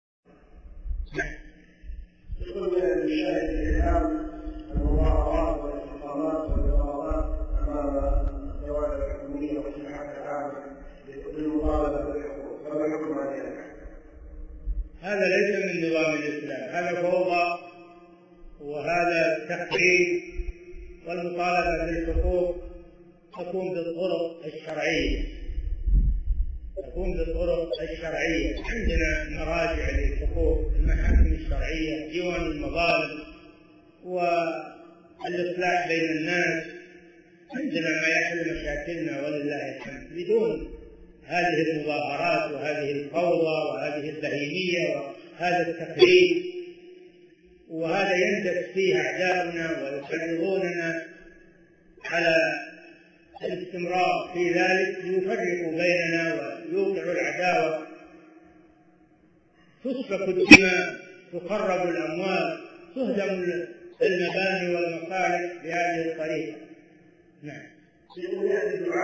حكم الإضرابات والاعتصامات في المراكز الحكومية مطالبة بالحقوق لقاء بالمعهد العلمي - العلاّمة صالح الفوزان